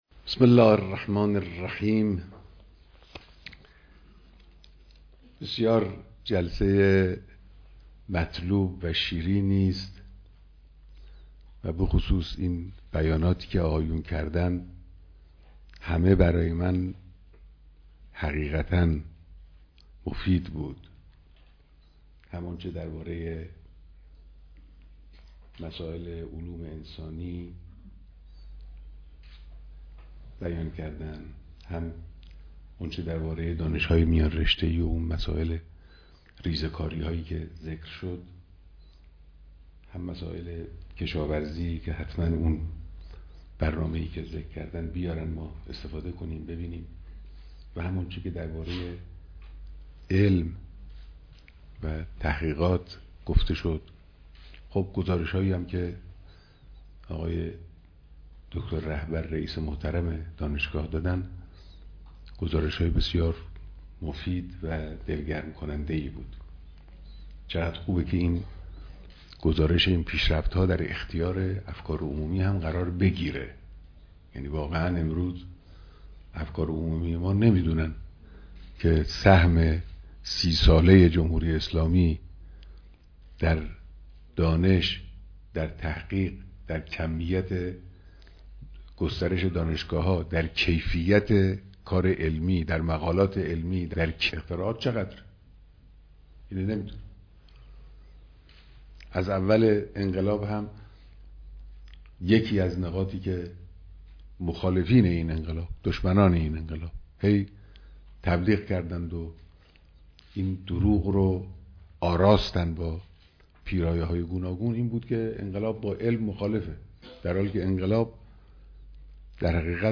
دیدار جمعی از اساتید، مسوولان و اعضای هیات علمی دانشگاه تهران